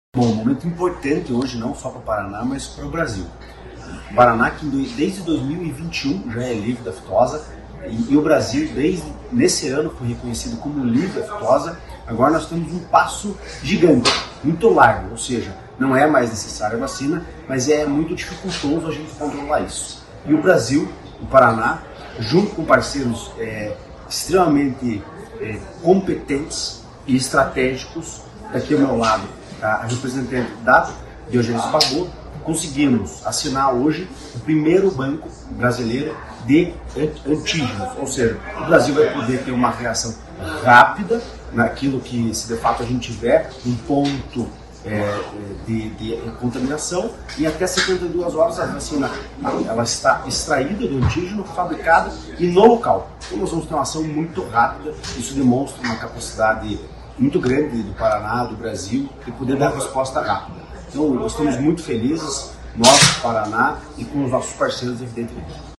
Sonora do diretor-presidente do Tecpar, Eduardo Marafon, sobre a criação do primeiro banco brasileiro de antígenos e vacinas contra febre aftosa pelo Tecpar